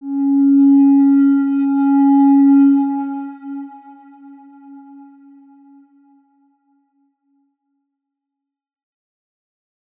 X_Windwistle-C#3-mf.wav